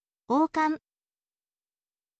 oukan